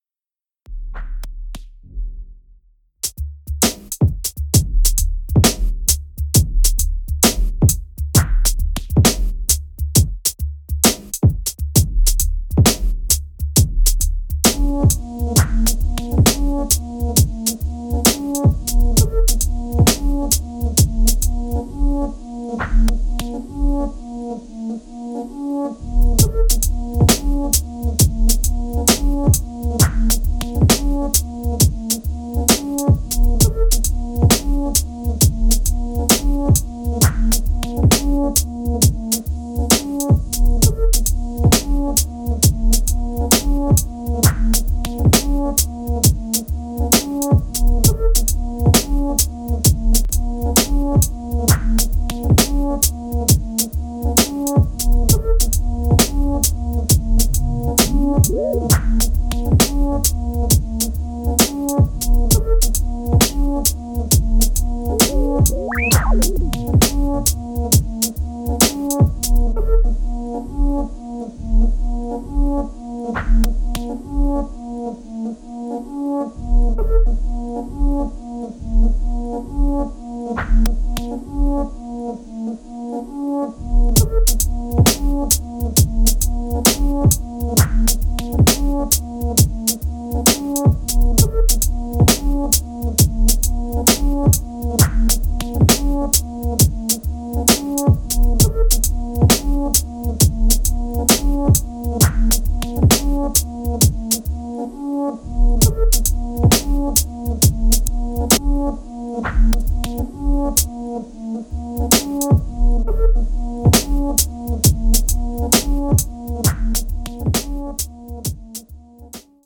show off their full range of breaky moods and tempos
Electronix House Bass Breaks